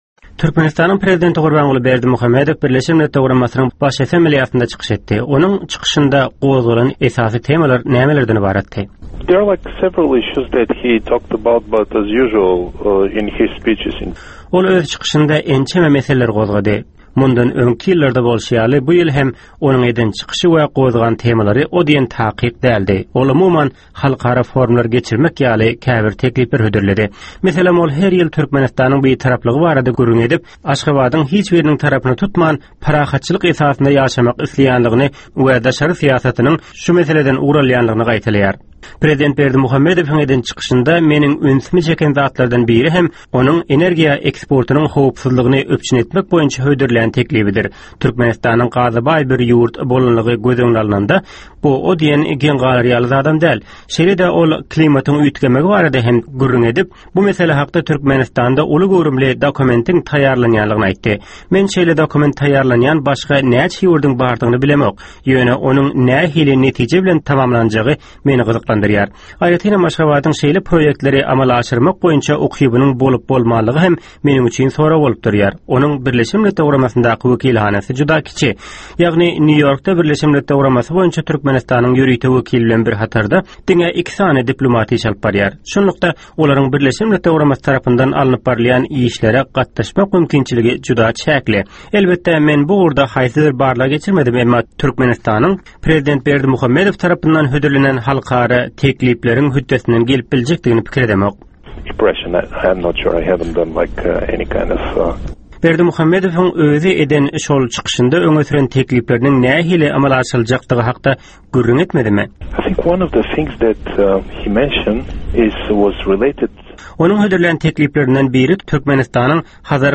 Prezident Berdimuhamedow BMG-niň Baş Assambleýasynyň 66-njy sessiýasynda çykyş etdi